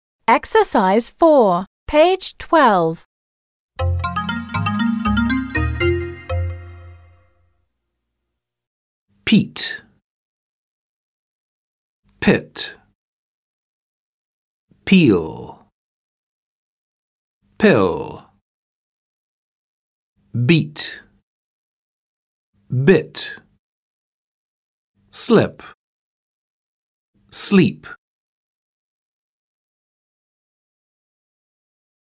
/ɪ/ (короткий звук):
/iː/ (долгий звук):